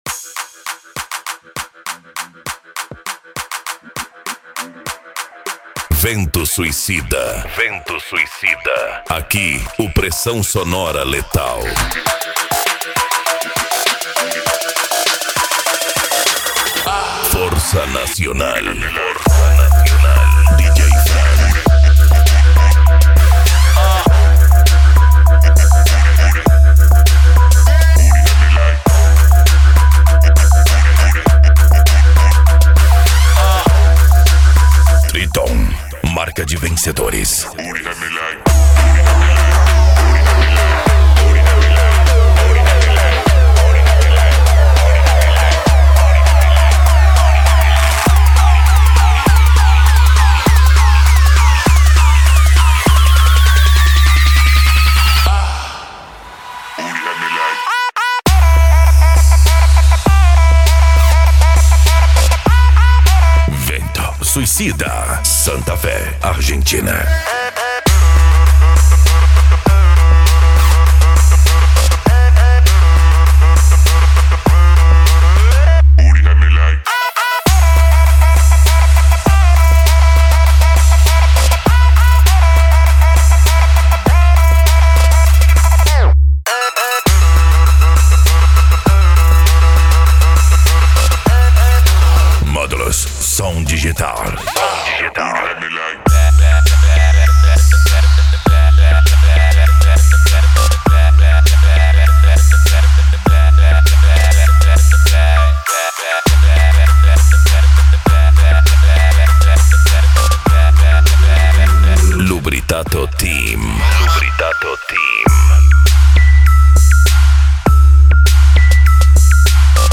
Bass
PANCADÃO
Psy Trance
Racha De Som